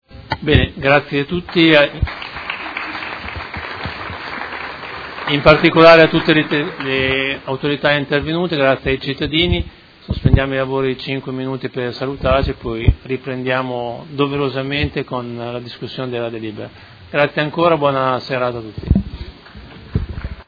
Seduta del 14/11/2019. Ringraziamenti ad autorità e cittadini